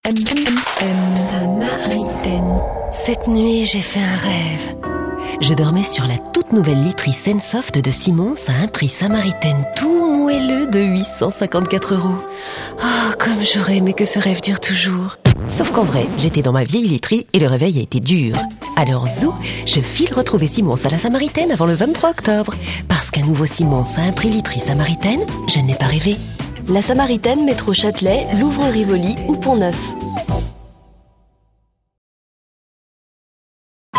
VOIX FEMININE FRANCAISE MEDIUM.PUBLICITES/FILMS INSTITUTIONNELS? E-LEARNONG, VOICE OVER, ETC...
Sprechprobe: Sonstiges (Muttersprache):
FRENCH FEMALE VOICE TALENT.